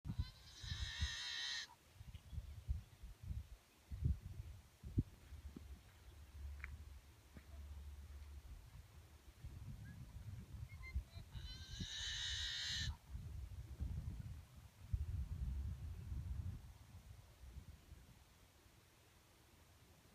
Cranky Baby or Yellow-Headed Blackbird?
Beautiful face with that raucous sound
yellow_headed-blackbird.wav